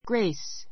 ɡréis グ レ イ ス